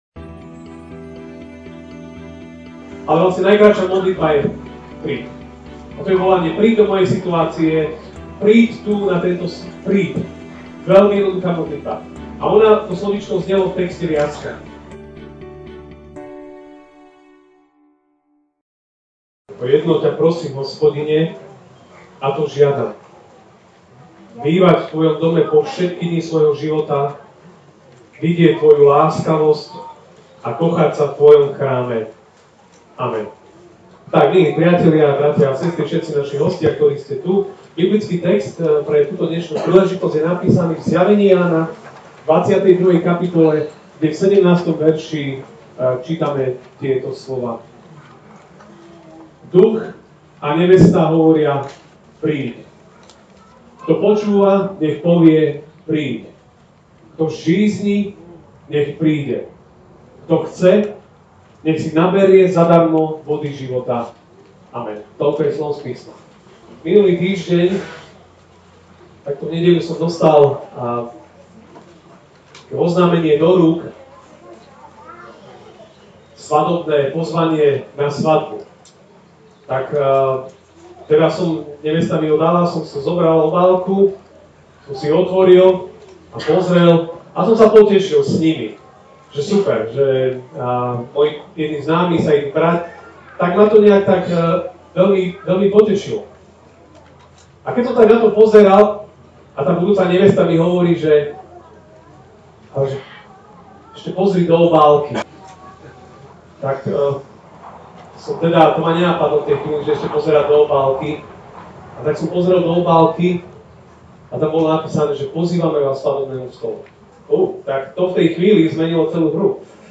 MP3 SUBSCRIBE on iTunes(Podcast) Notes Sermons in this Series Ranná kázeň: Len jedno je potrebné: Prísť! (Zj 22, 17) Duch a nevesta hovoria: Príď! Kto počúva, nech povie: Príď! Kto žízni, nech príde! Kto chce, nech si naberie zadarmo vodu života!